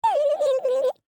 turkey-v2.ogg